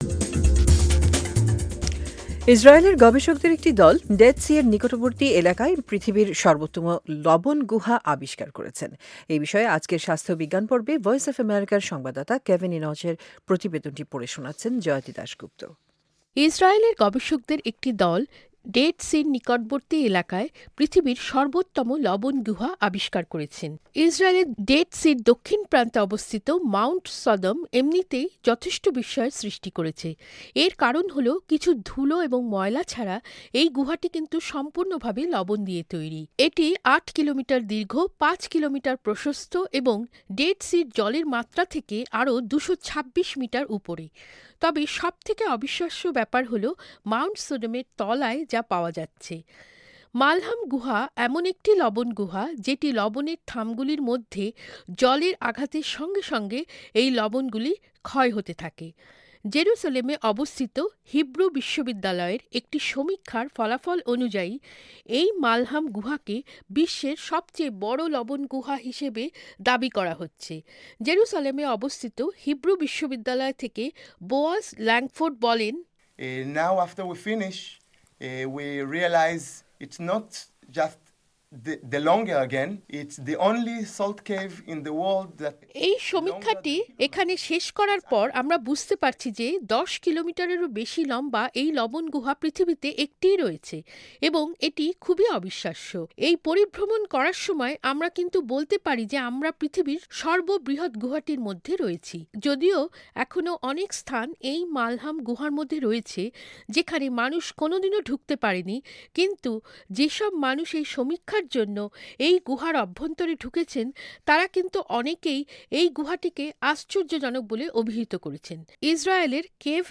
বিজ্ঞান ও প্রযুক্তি পর্বে প্রতিবেদনটি পড়ে শোনাচ্ছেন